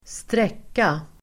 Uttal: [²str'ek:a]